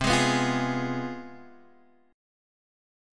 Fdim/C chord